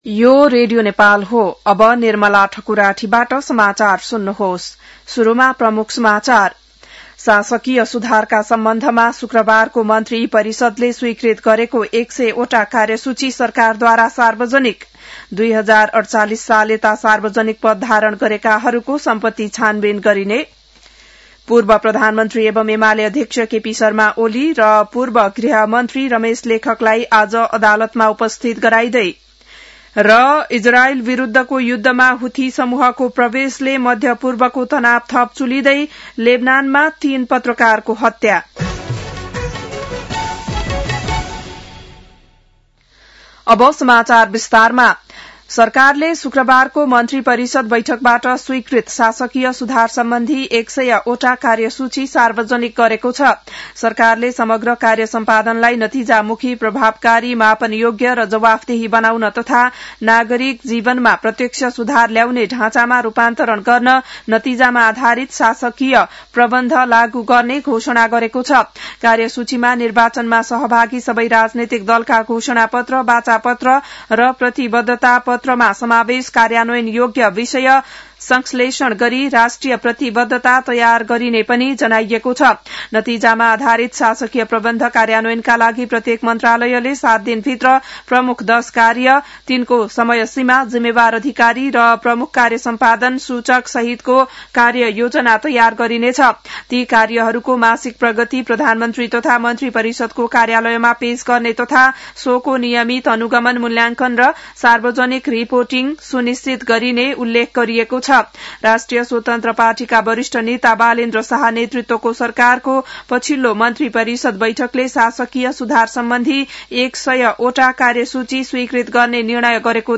An online outlet of Nepal's national radio broadcaster
बिहान ९ बजेको नेपाली समाचार : १५ चैत , २०८२